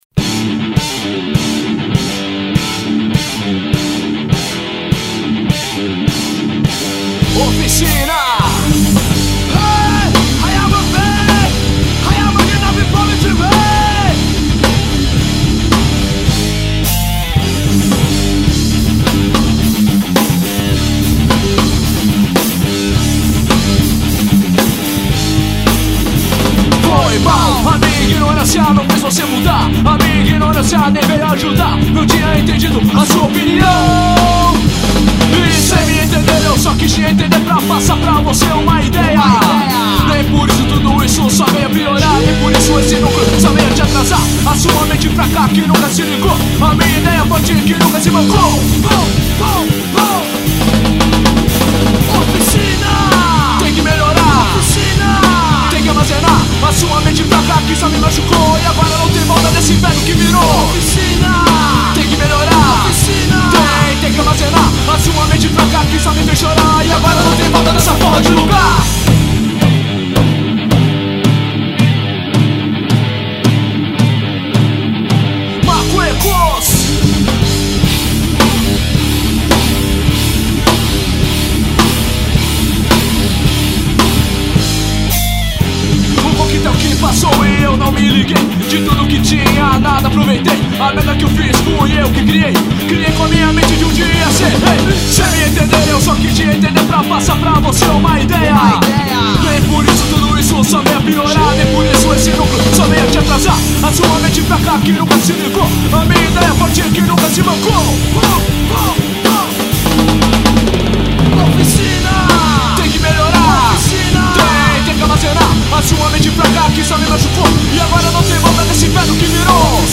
EstiloNew Metal